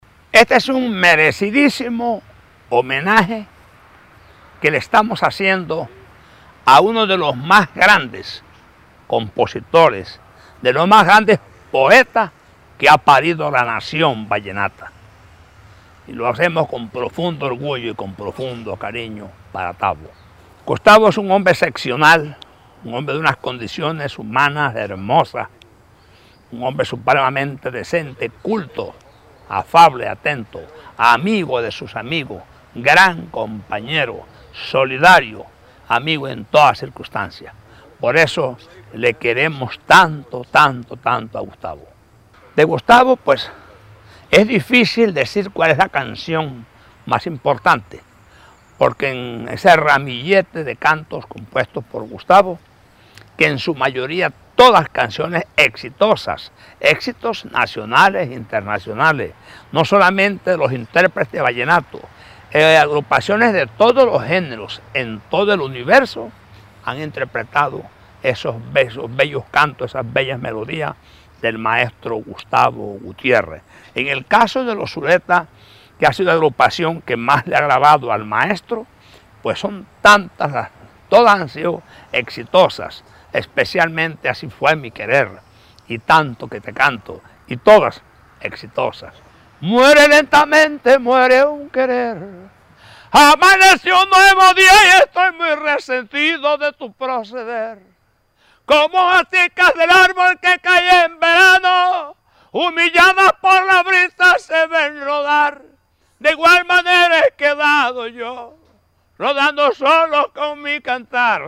entrevista-poncho-zuleta-fflv.mp3